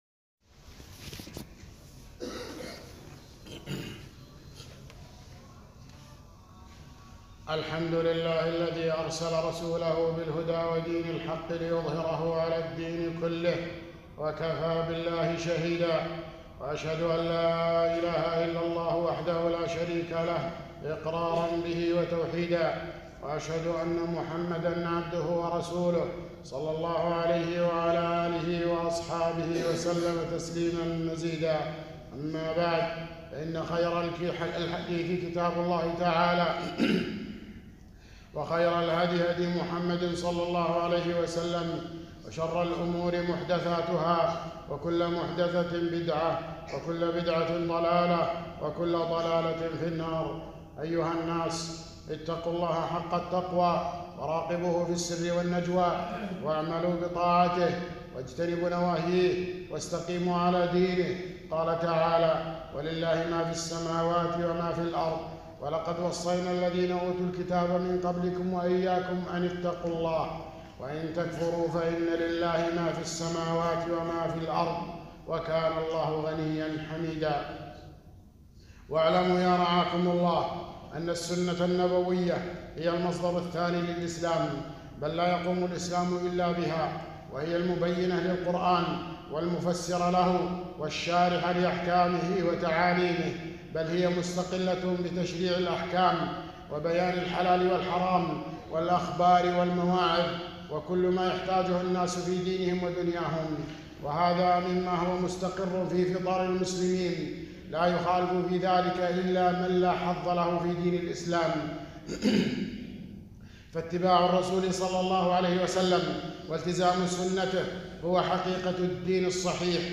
خطبة - حجية السنة وبيان حال القرآنيين